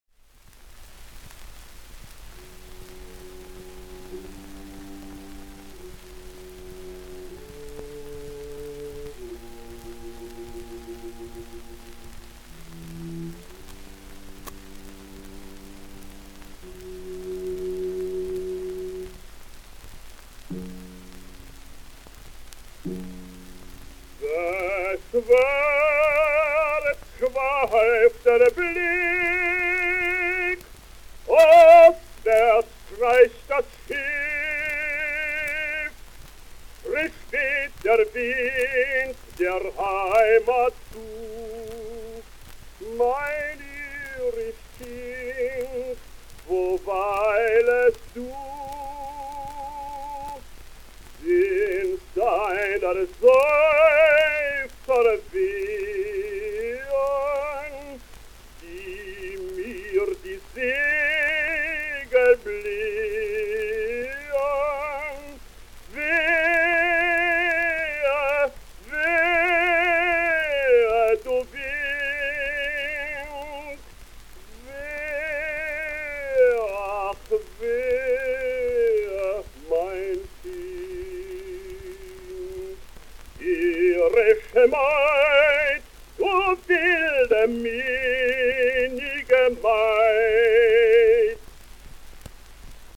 He made his debut in 1895 as Gomez (Das Nachtlager in Granada by Conradin Kreutzer) in Wrocław/Breslau. 1896 to 1900, he was a lyrical tenor at the opera in Köln, where he switched to the buffo repertoire.